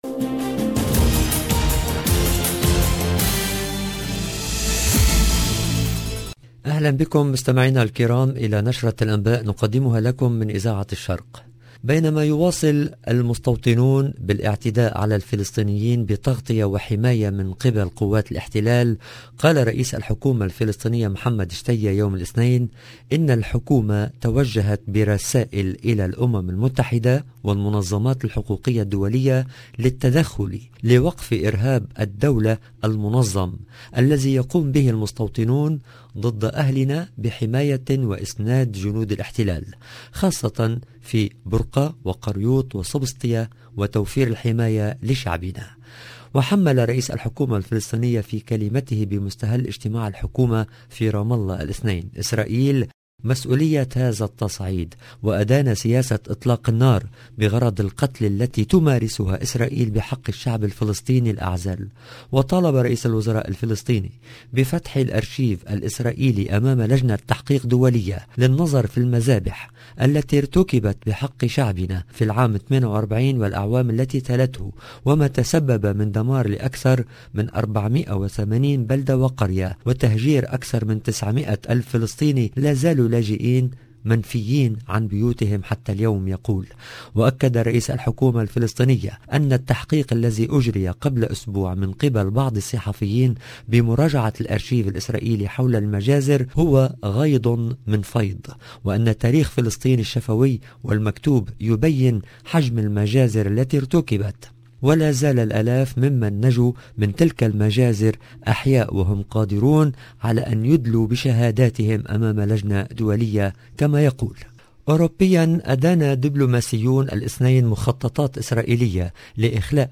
LE JOURNAL DU SOIR EN LANGUE ARABE DU 20/12/21